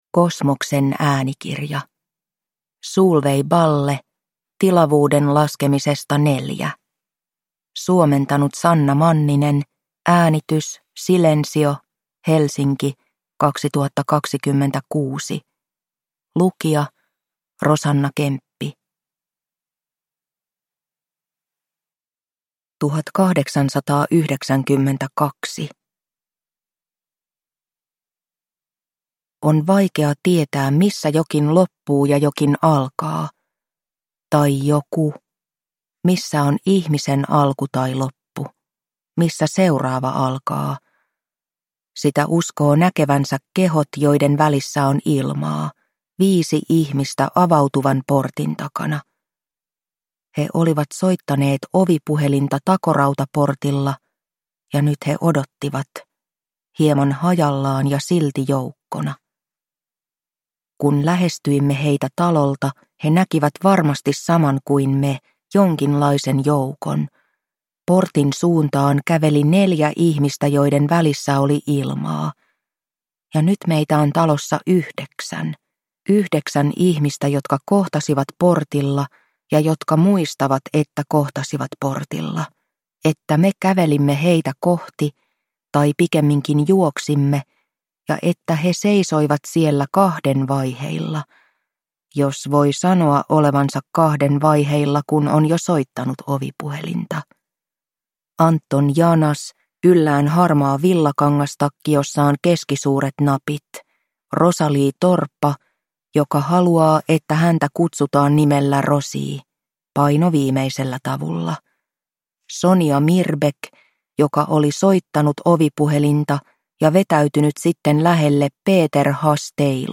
Tilavuuden laskemisesta IV – Ljudbok